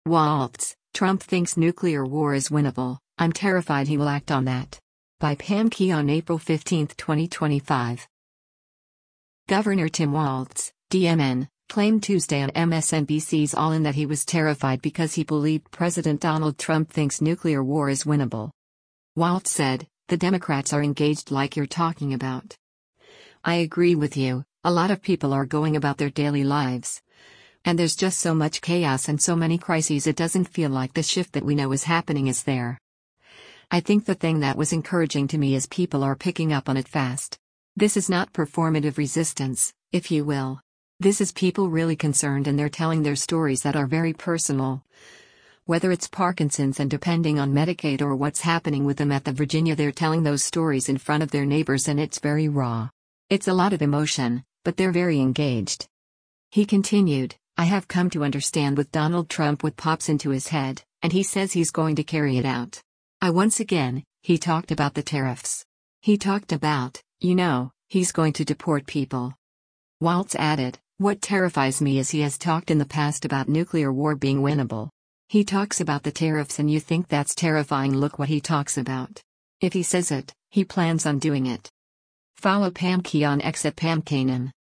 Governor Tim Walz (D-MN) claimed Tuesday on MSNBC’s “All In” that he was terrified because he believed President Donald Trump thinks nuclear war is winnable.